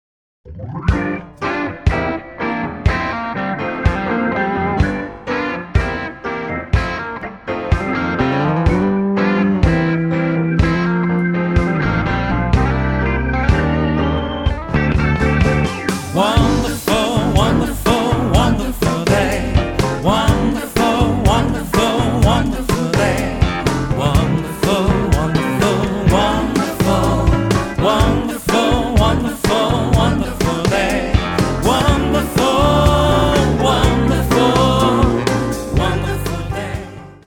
熱く透明な歌声と胸に迫る賛美CD!
日本をベースとして活動する韓国出身のゴスペルシンガー。